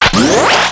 assets/ctr/nzportable/nzp/sounds/weapons/raygun/close.wav at 9ea766f1c2ff1baf68fe27859b7e5b52b329afea